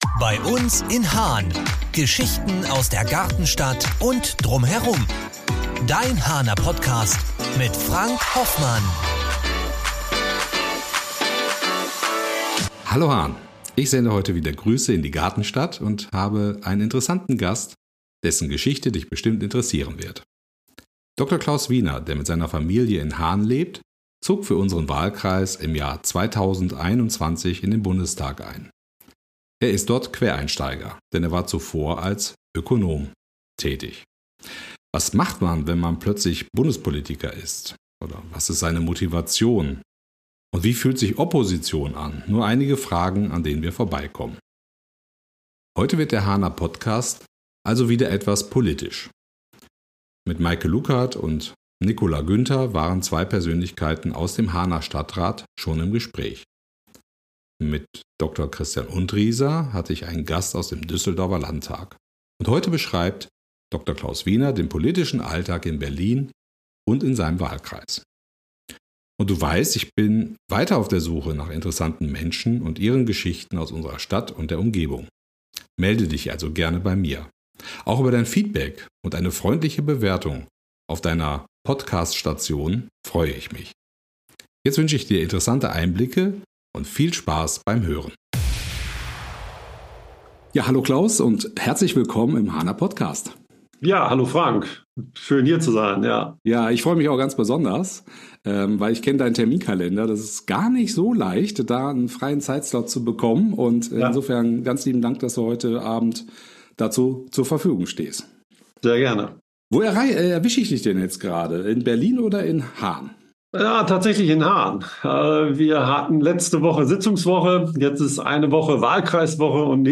Im Gespräch mit Dr. Klaus Wiener (MdB)